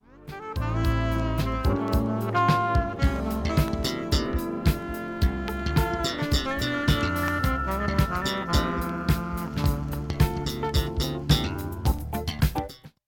聴き取り出来るか不安なレベルです。
A-2中盤にかすかなプツが２回出ます。
フューチャリングした極上メロウ